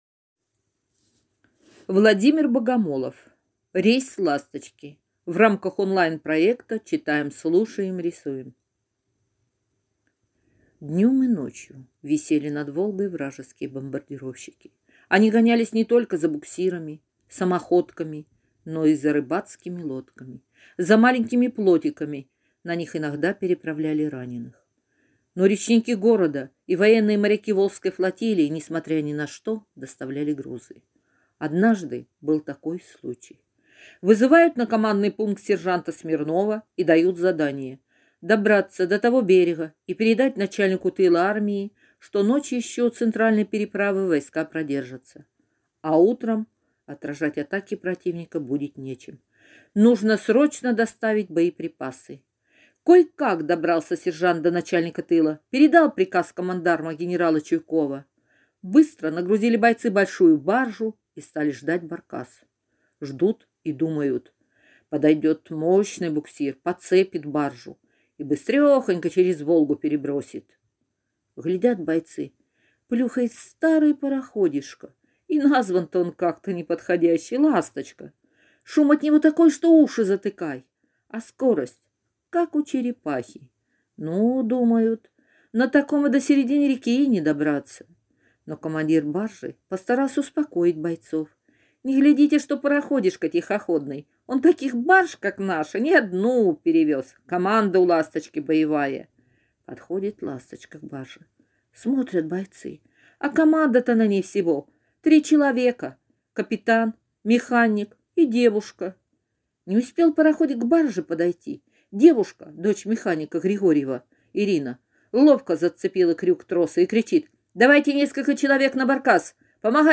Аудиорассказ «Рейс «Ласточки»» – Богомолов В.